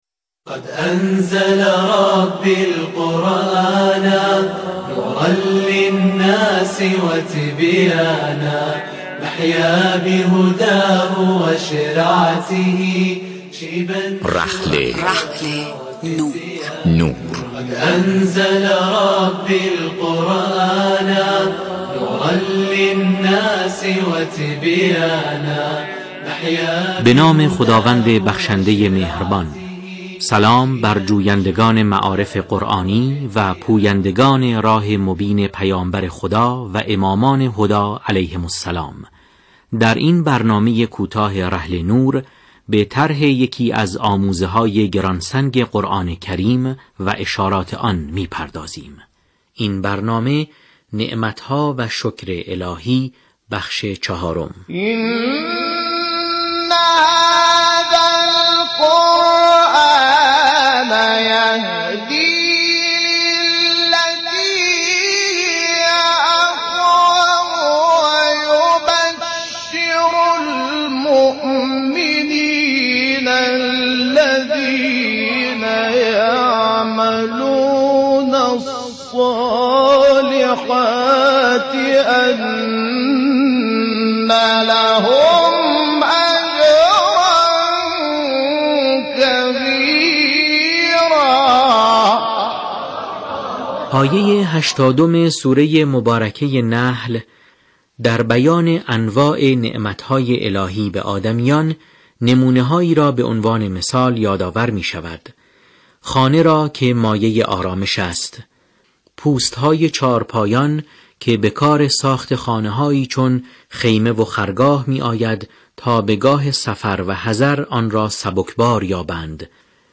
به گزارش ایکنا، رحل نور، عنوان میان‌برنامه شبکه رادیویی قرآن است که شنبه تا چهارشنبه‌ها طی بازه زمانی 10دقیقه‌ای به صورت تولیدی پخش می‌شود.